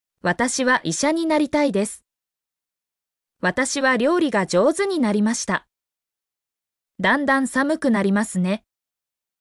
mp3-output-ttsfreedotcom-57_zjYFoIlb.mp3